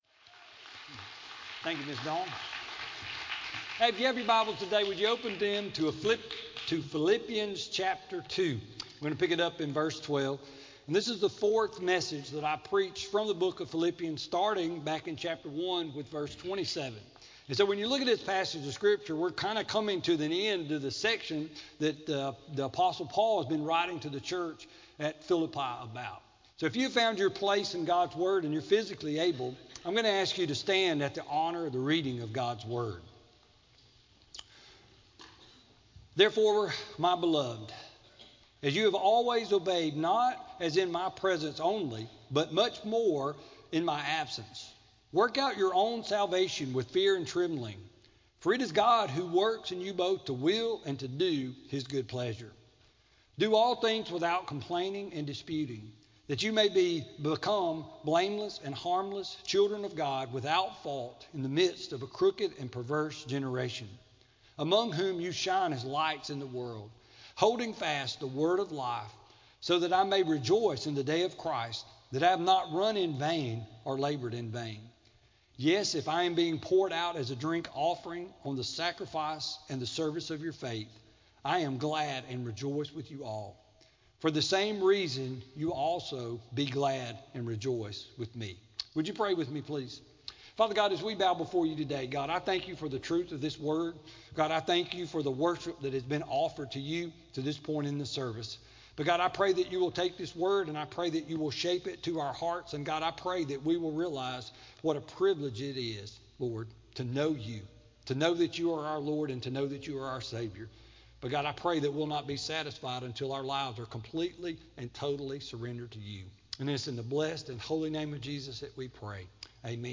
Sermon-2-7-16-CD.mp3